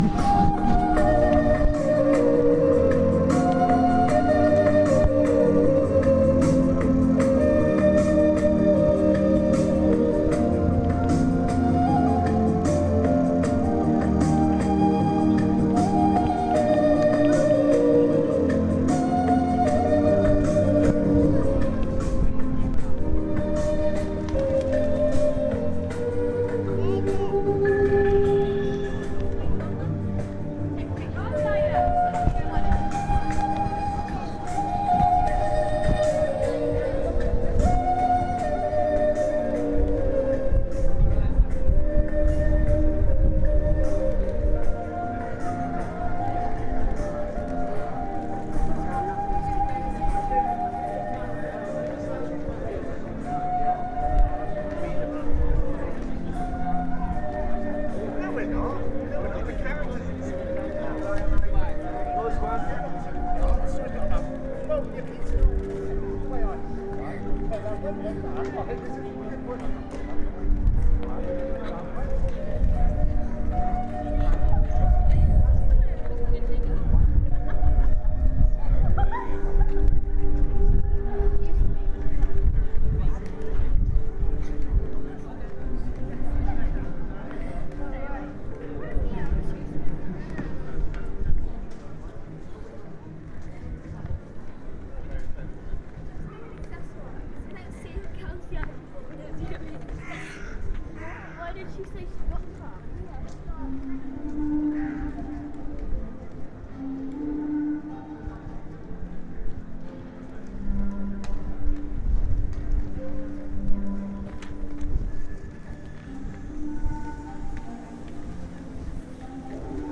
Panpipes on Worcester High Street this afternoon 7. Recorded as I walked away. Kept recording in hope of clock strikes.